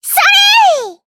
Taily-Vox_Attack4_jp.wav